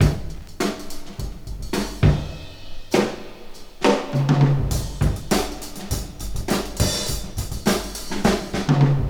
• 106 Bpm Breakbeat Sample G Key.wav
Free drum loop sample - kick tuned to the G note. Loudest frequency: 1292Hz
106-bpm-breakbeat-sample-g-key-SjZ.wav